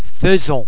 ·[ ai ]